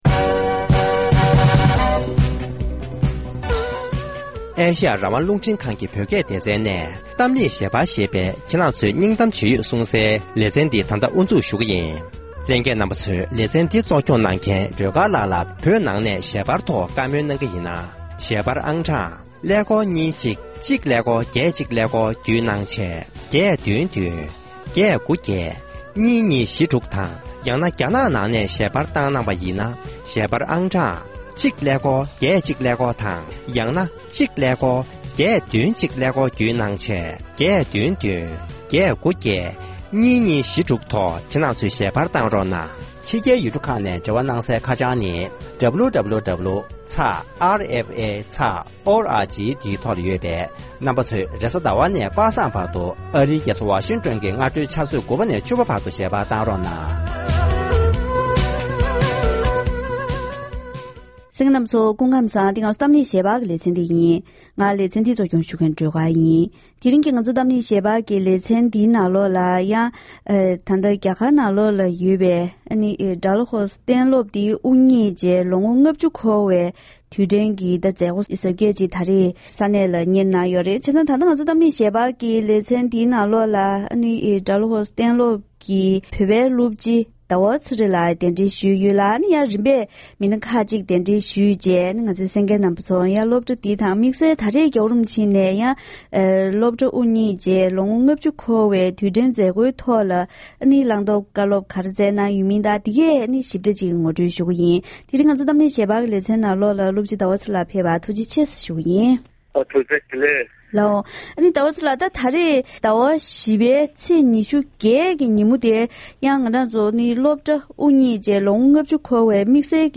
ཌལ་ཧོར་གཏན་སློབ་དབུ་བརྙེས་ནས་ལོ་ངོ་༥༠འཁོར་བའི་མཛད་སྒོའི་ཐོག་༸གོང་ས་མཆོག་ནས་སྩལ་བའི་བཀའ་སློབ།